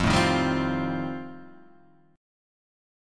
E#79 chord